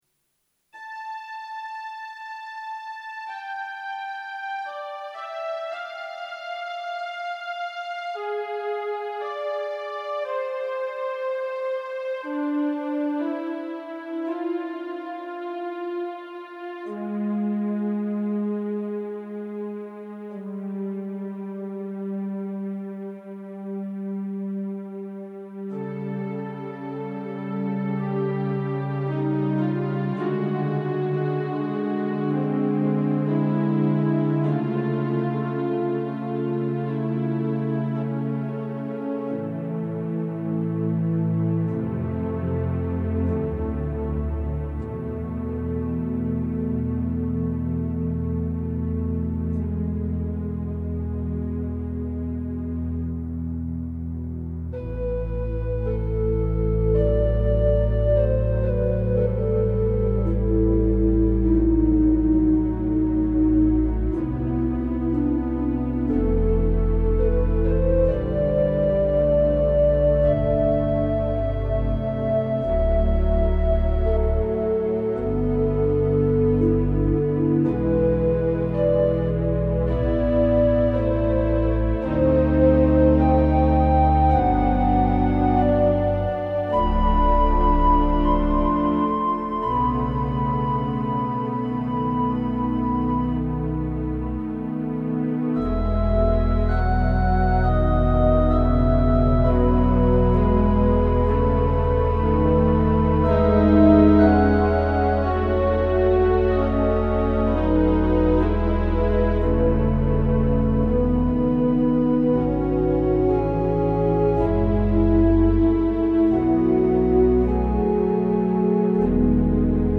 Organ Meditations Audio Gallery
Peaceful works for quiet reflection